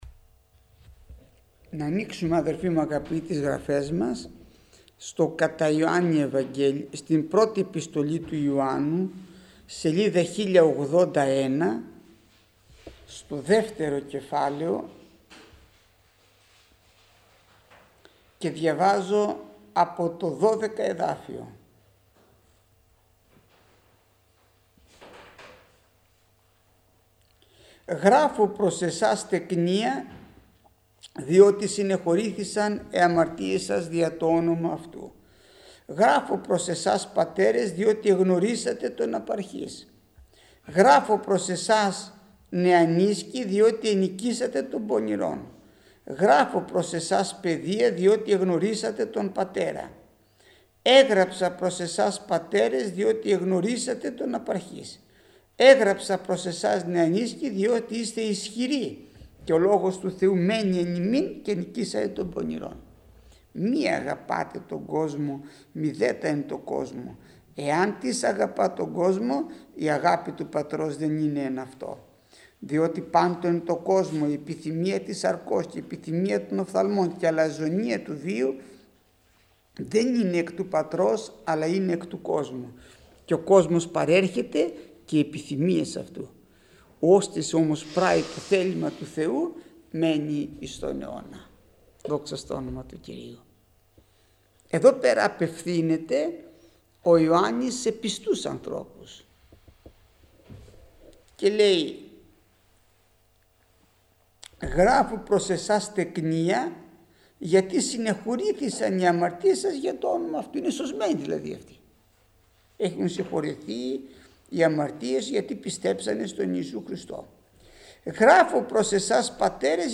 Μήνυμα πριν τη Θεία Κοινωνία